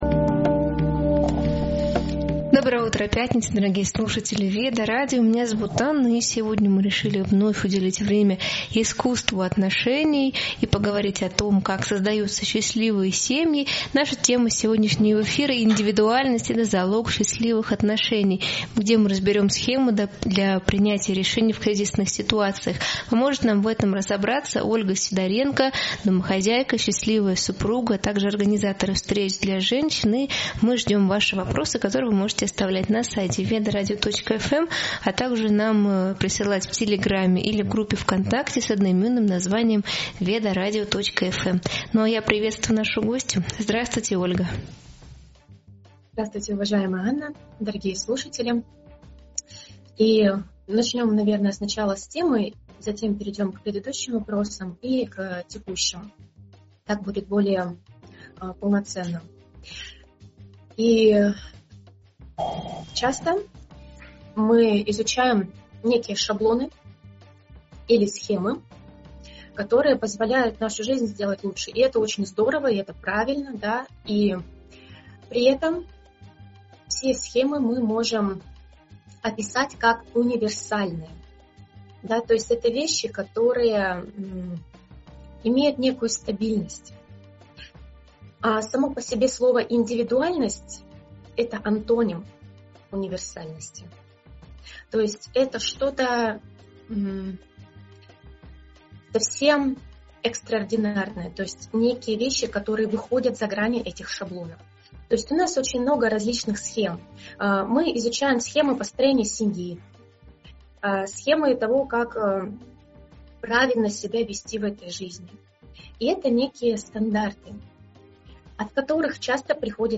Эфир посвящён теме индивидуальности как основы гармоничных отношений. Обсуждаются вопросы самопознания, баланса между «хочу» и «надо», личных границ и заботы о себе.